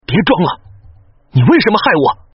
分段配音